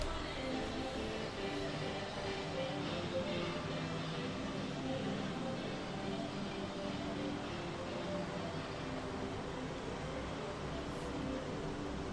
I didn't get to it in time but this is someone's speaker, on the back of their bike out in my street.